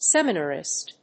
音節sem・i・nar・ist 発音記号・読み方
/sémənərɪst(米国英語)/
seminarist.mp3